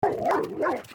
Dog Barking 04 Sound Effect Download: Instant Soundboard Button
Dog Barking Sound3,663 views